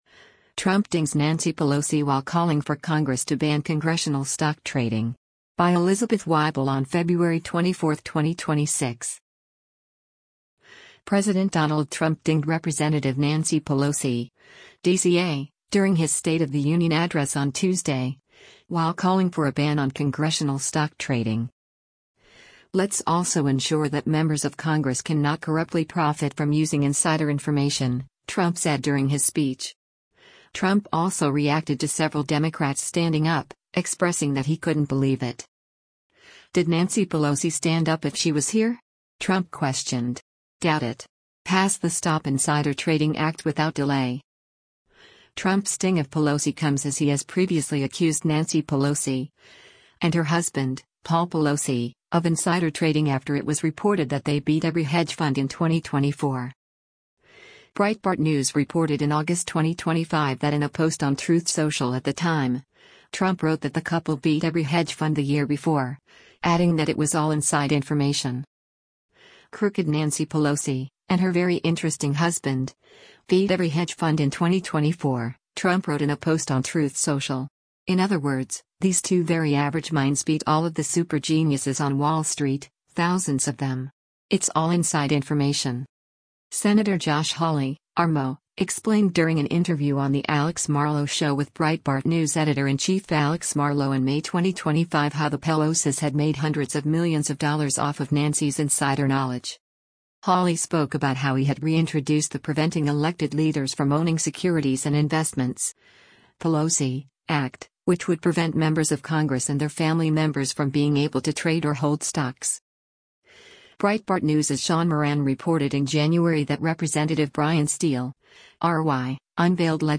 President Donald Trump dinged Rep. Nancy Pelosi (D-CA) during his State of the Union address on Tuesday, while calling for a ban on congressional stock trading.